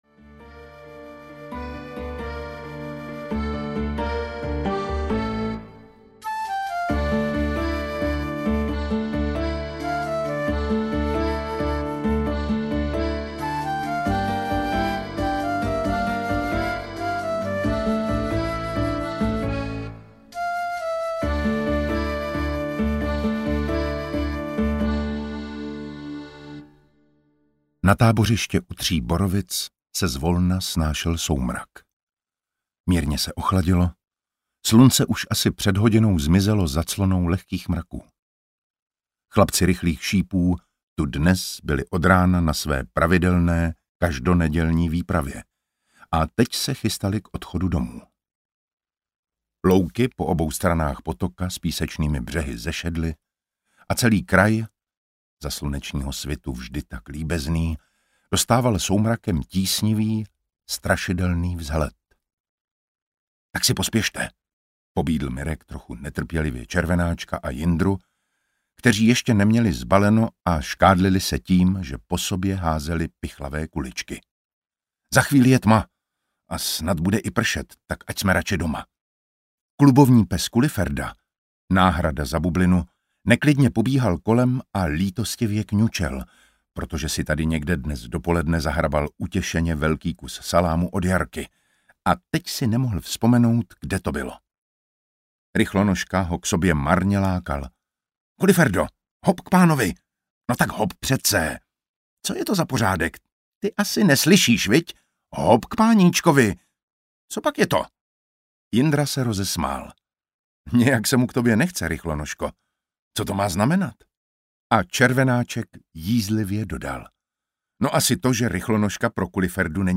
Tajemství Velkého Vonta audiokniha
Ukázka z knihy
• InterpretDavid Matásek